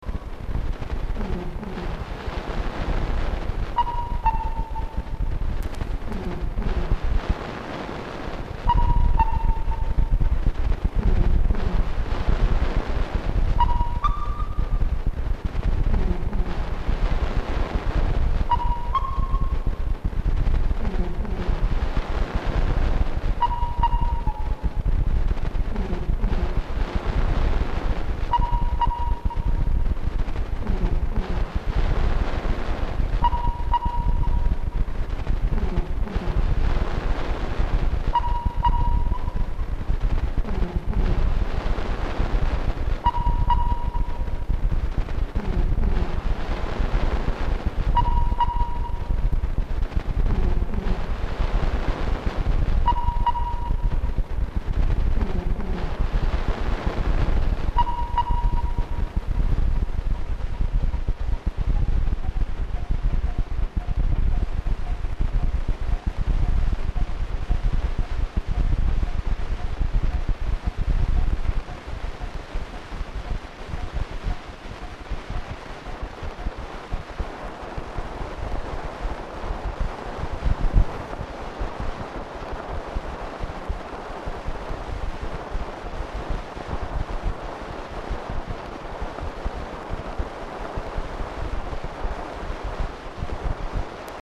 Projecting Light and Sound Since 1995